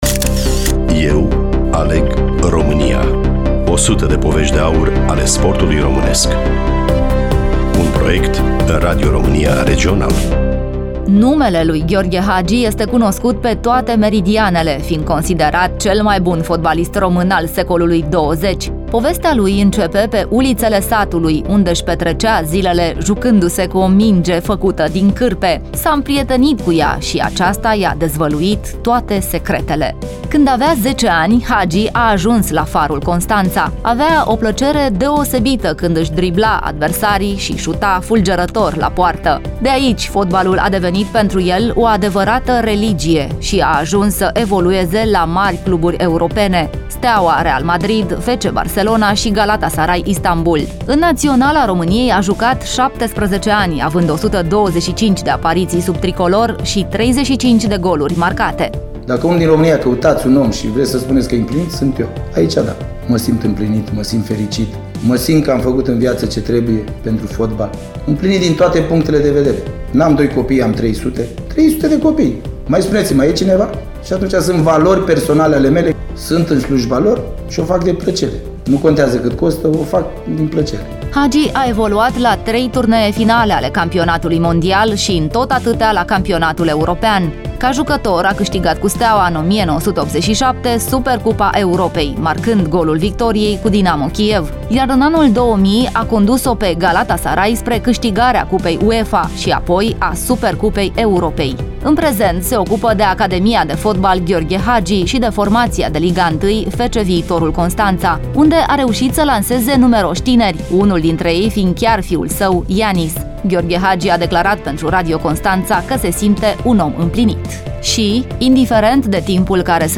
Gheorghe Hagi a declarat, pentru Radio Constanța, că se simte un om împlinit: “Dacă acum, în România, căutaţi un om şi vreţi să spuneţi despre el că e împlinit, acela sunt eu.
Studiou: Radio Constanta